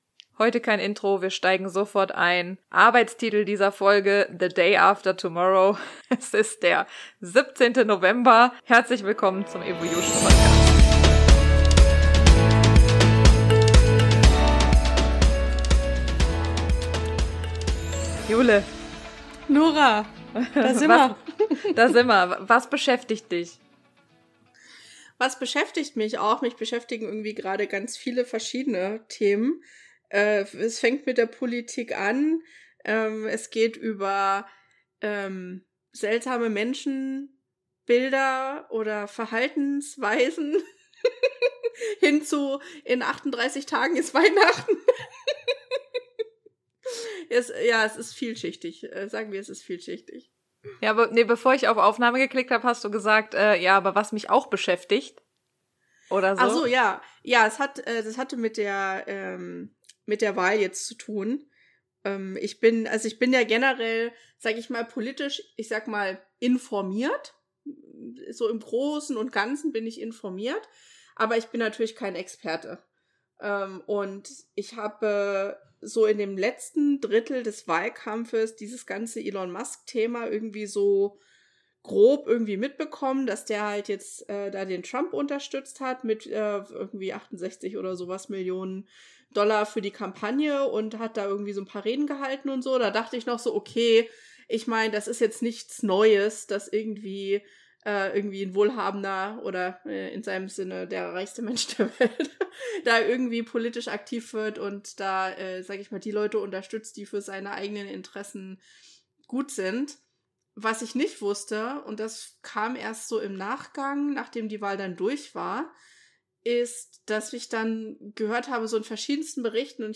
Wir haben uns am Sonntag hingesetzt, kurz vor Veröffentlichung, und einfach drauf losgequatscht.
Eine Folge voller persönlicher Updates, Einblicke und natürlich jeder Menge Lacher – fast wie ein gemütliches Gespräch mit Freunden!